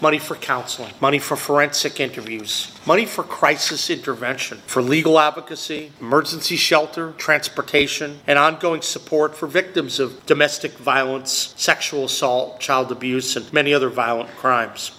Governor Patrick Morrisey announced that he has awarded $16.3 million dollars in West Virginia Victims of Crime Act grants to 83 projects across the state. At a press conference in Parkersburg, the governor said the funds would support assistance to victims as they move through the criminal justice system to make sure they have access to the help they need…